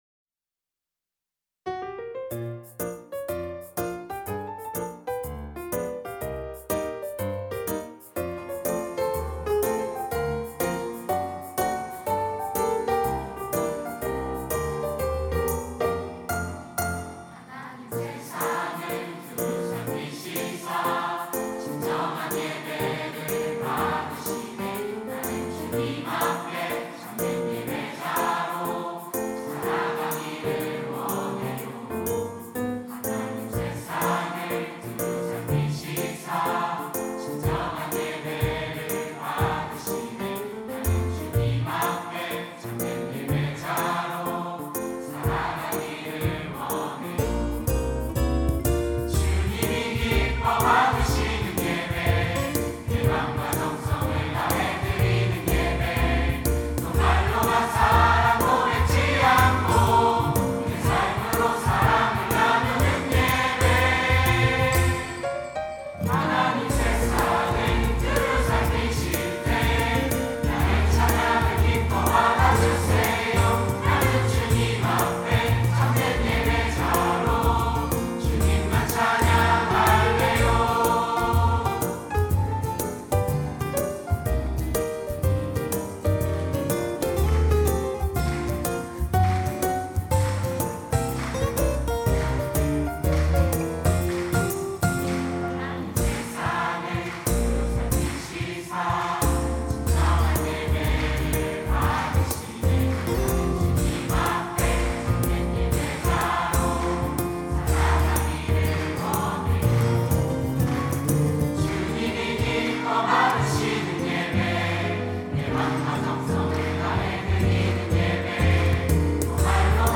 특송과 특주 - 참된 예배자
중등부 학생, 학부모, 교사